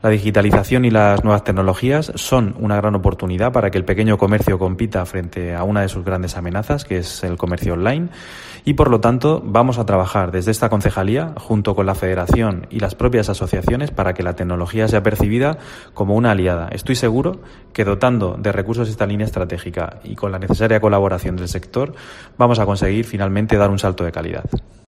Juan Fernando Hernández, concejal de Comercio, Mercados y Vía Pública